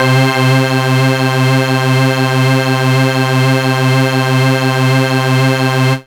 SYNTH GENERAL-2 0004.wav